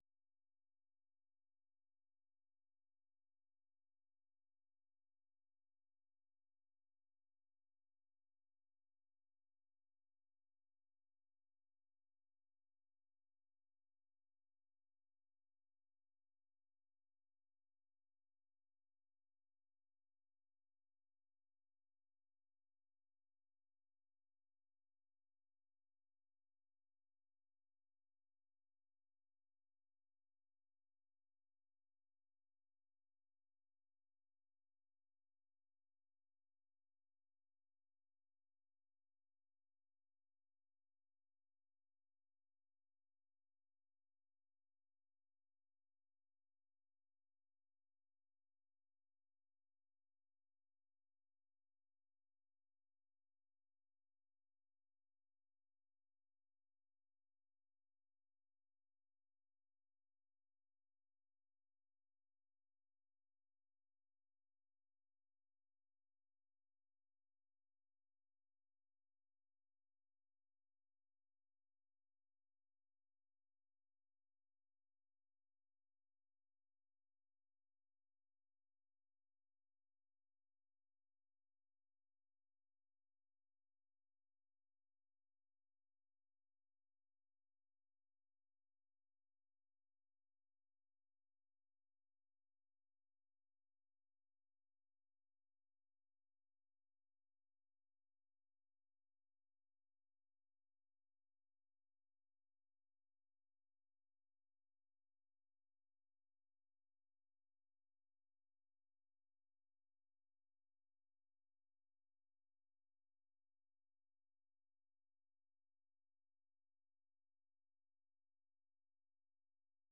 ဗွီအိုအေမြန်မာပိုင်းရဲ့ ညပိုင်း မြန်မာစံတော်ချိန် ၉ နာရီမှ ၁၀နာရီအထိ ရေဒီယိုအစီအစဉ်ကို ရေဒီယိုကနေ ထုတ်လွှင့်ချိန်နဲ့ တပြိုင်နက်ထဲမှာပဲ Facebook နဲ့ Youtube ကနေလည်း တိုက်ရိုက် ထုတ်လွှင့်ပေးနေပါတယ်။